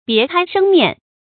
注音：ㄅㄧㄝ ˊ ㄎㄞ ㄕㄥ ㄇㄧㄢˋ
別開生面的讀法